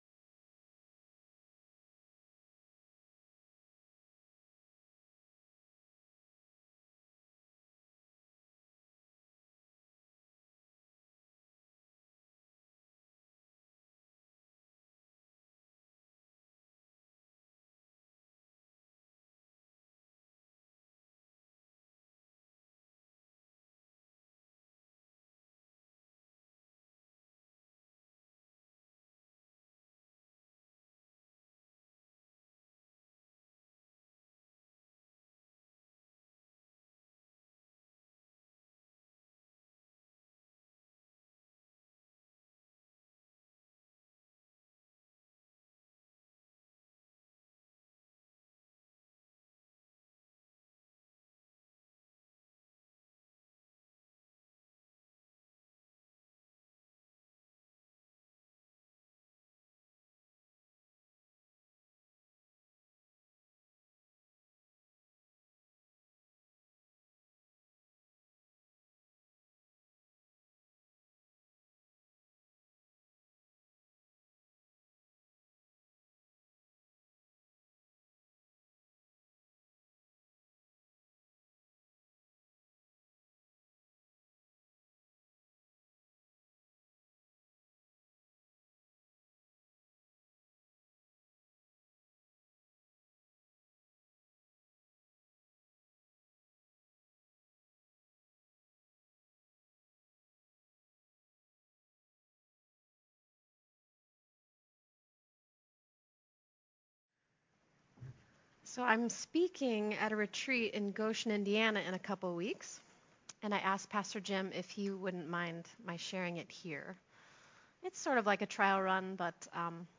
Praise-Sermon-May-1-2022-CD.mp3